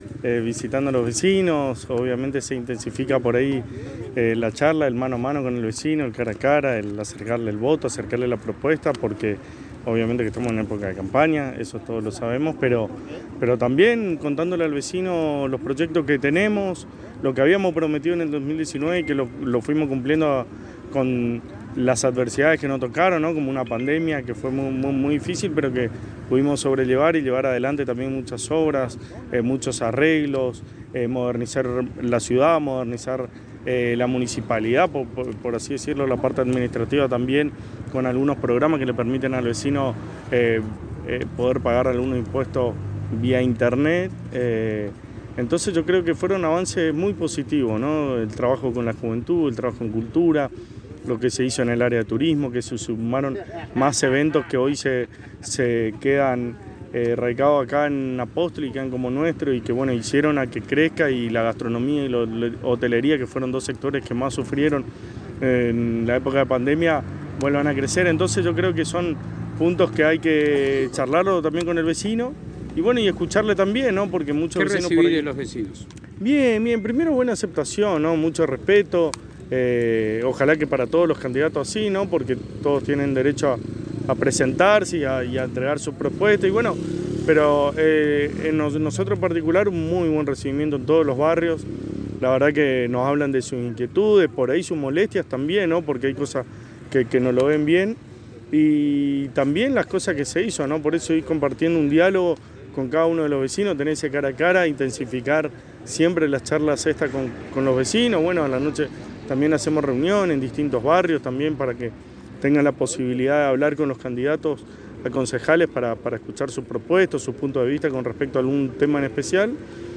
En diálogo exclusivo de la ANG con el secretario de Gobierno del Municipio de Apóstoles Gastón Casares en medio de una de las tantas caminatas en los barrios el mismo manifestó que la charla con los vecinos siempre es productiva porque pueden cara cara contarles sobre todo lo que se ha hecho en la gestión a pesar de las dificultades que les tocó superar en la gestión siempre se trabajó para cumplir el contrato social hecho con el apostoleño en el año 2019.